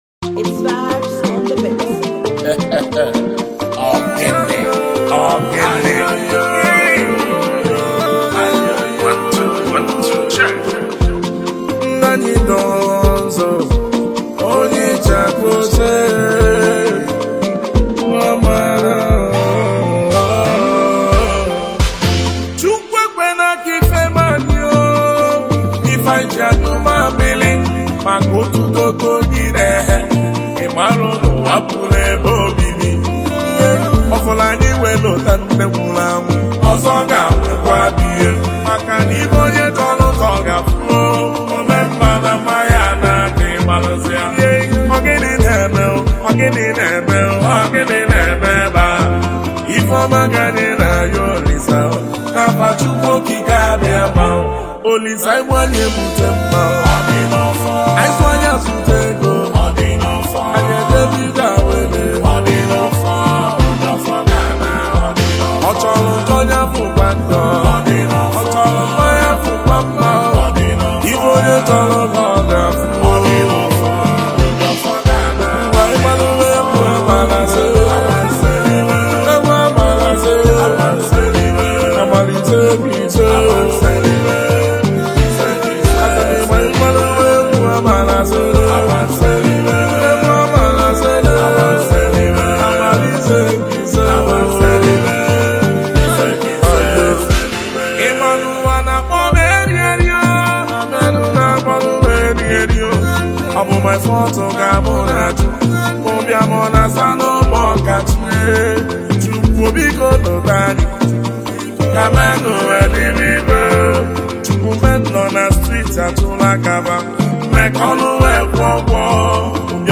highlife
Eastern highlife